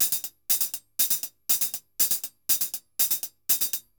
HH_Baion 120_1.wav